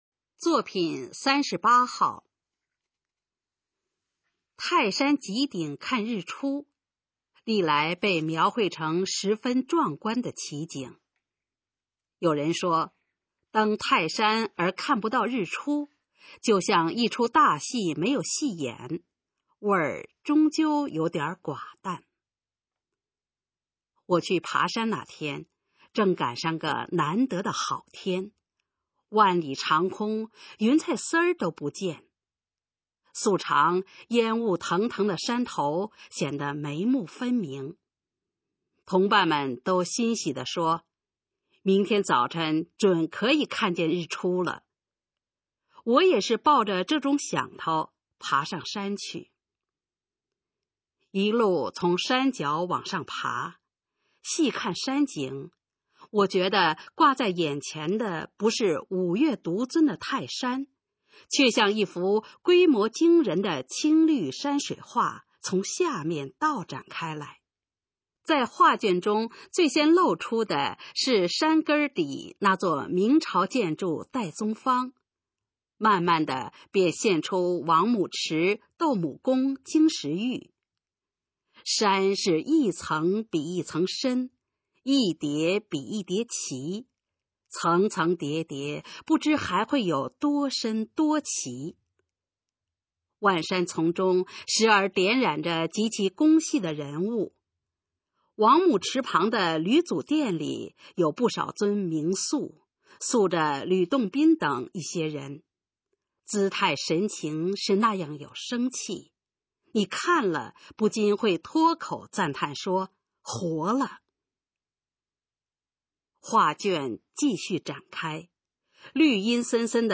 首页 视听 学说普通话 作品朗读（新大纲）
《泰山极顶》示范朗读_水平测试（等级考试）用60篇朗读作品范读